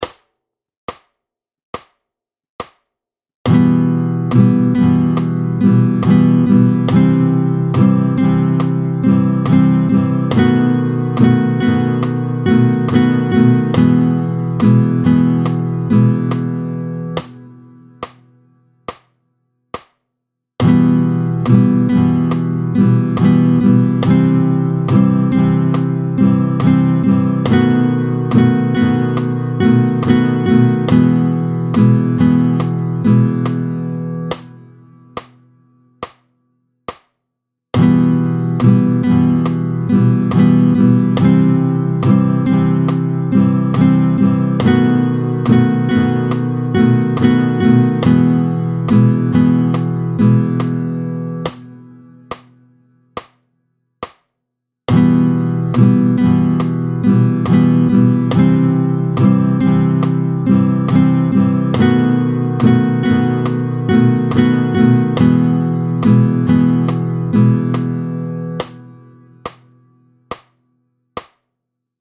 15-02 Rythmique.
tempo 70